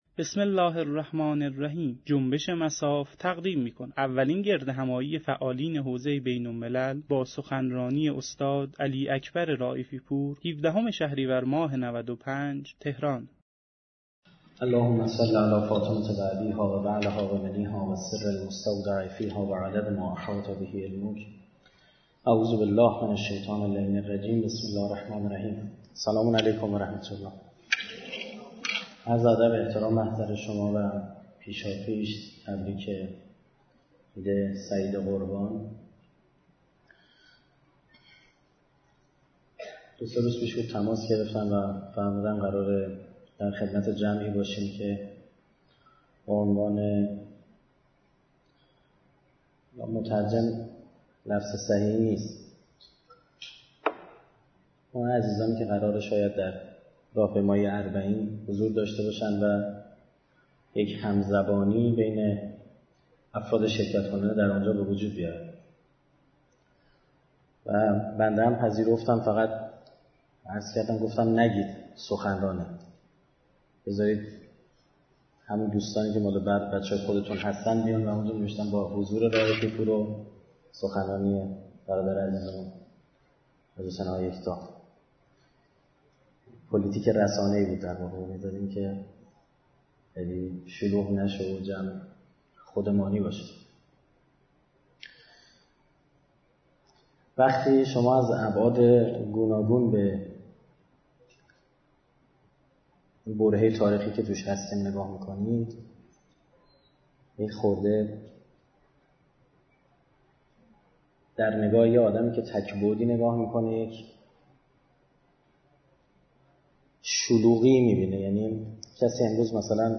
سخنرانی
گردهمایی فعالان حوزه بین الملل